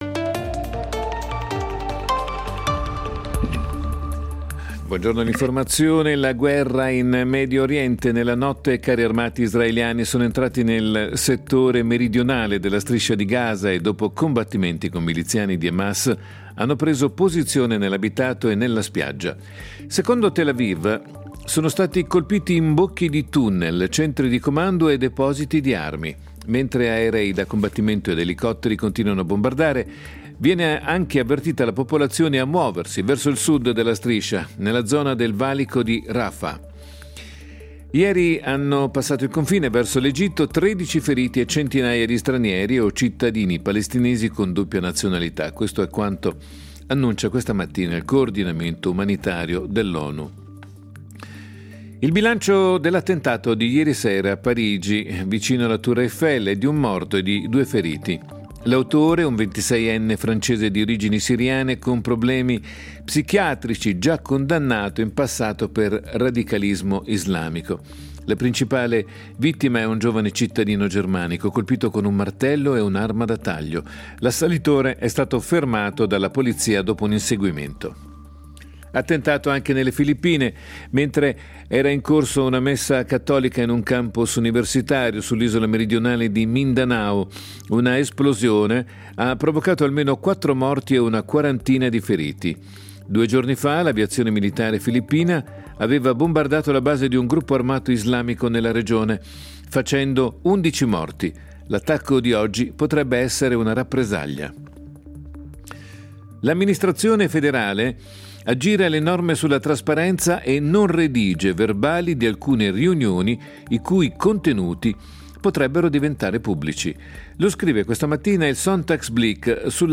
Notiziario delle 11:00 del 03.12.2023